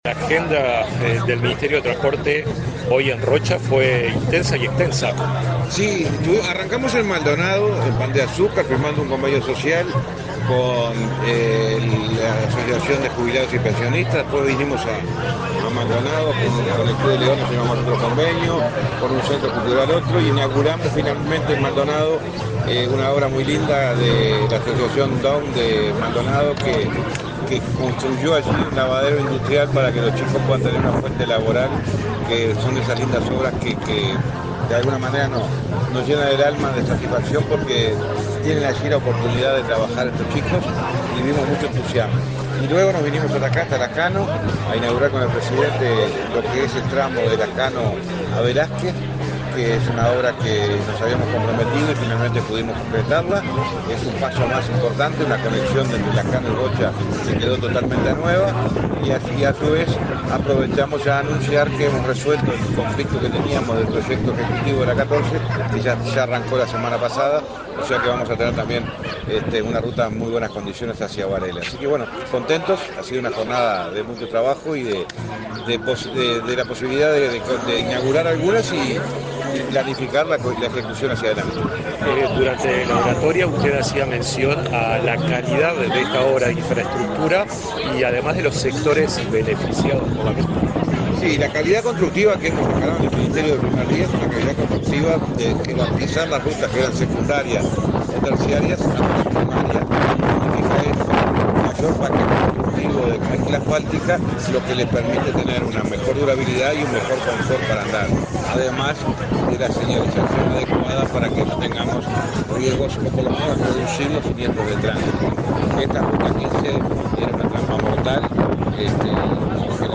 Entrevista al ministro del MTOP, José Luis Falero
Entrevista al ministro del MTOP, José Luis Falero 08/11/2024 Compartir Facebook X Copiar enlace WhatsApp LinkedIn Tras participar en la inauguración de obras en la ruta n.°15, en la localidad de Lascano, Rocha, este 7 de noviembre, con la presencia del presidente de la República, Luis Lacalle Pou, el titular del Ministerio de Transporte y Obras Públicas (MTOP), José Luis Falero, realizó declaraciones a Comunicación Presidencial.